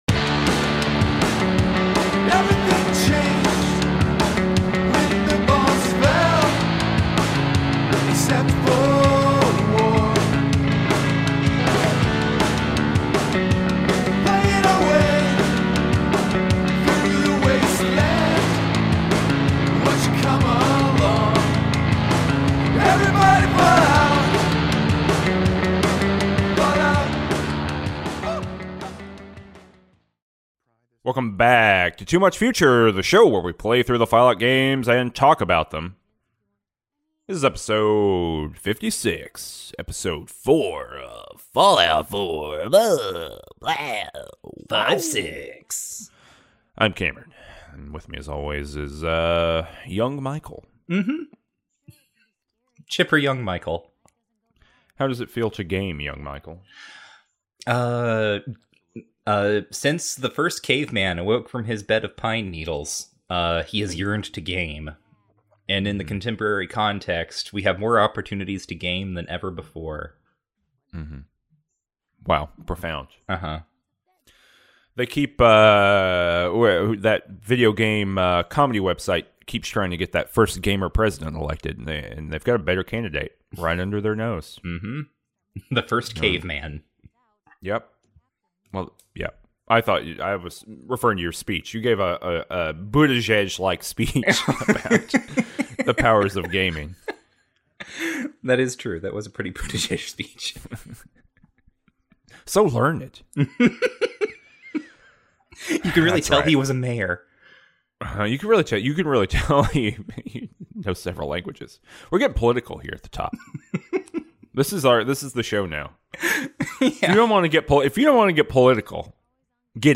We fix the radio and help the Brotherhood of Steel. Also the audio here was messed up and I had to fix it too.